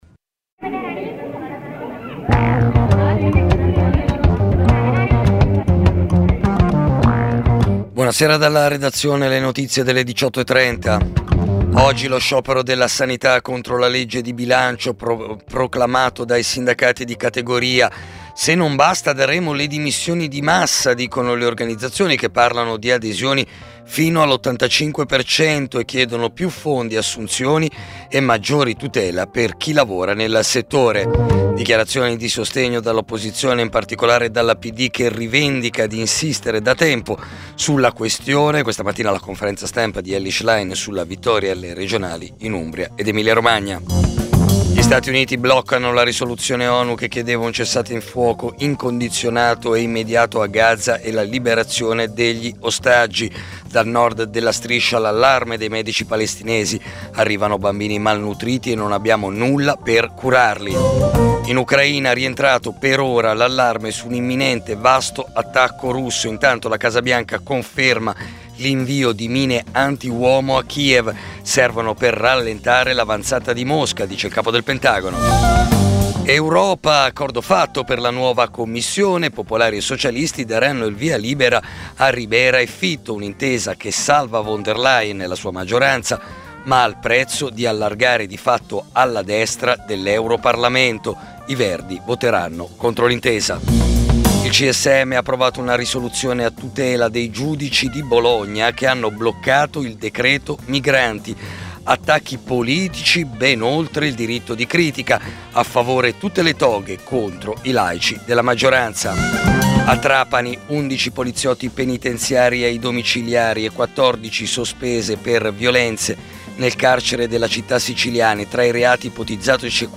Giornale radio Nazionale